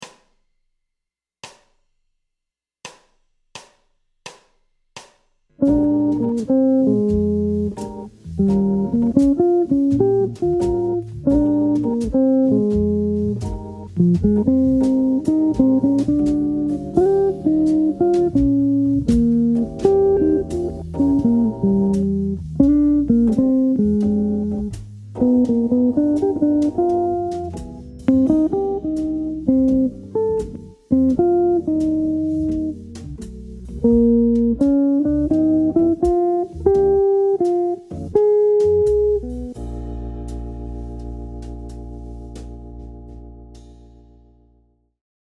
Un trait mixolydien avec un chromatisme ascendant vers la Tierce.
Exemple d’emploi du Lick dans un Blues en Bb
Blues-Lick-22-1.mp3